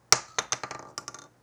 shotgun-shell-ejection-2.wav